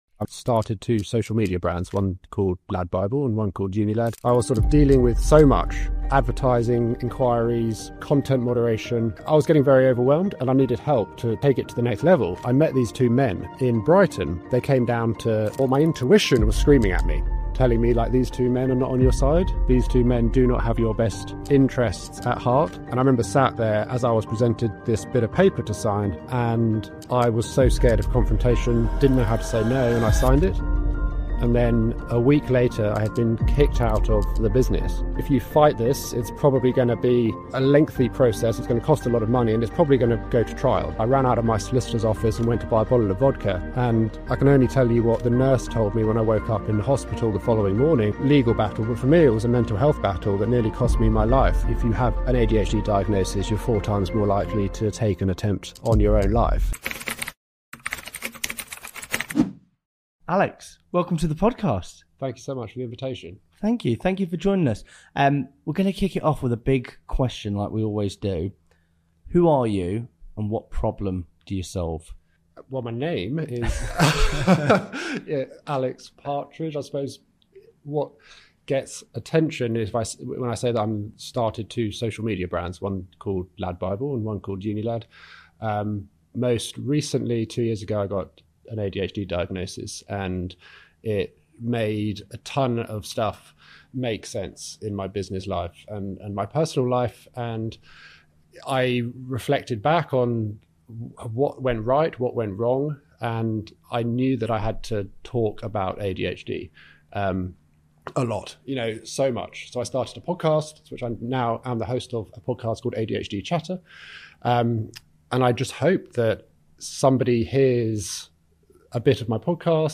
In this episode, we sit down with Alex Partridge — the mind behind Unilad, LadBible, and the hit podcast ADHD Chatter — to talk about late diagnosis, creative chaos, and what happens when you finally realise… it wasn’t just you.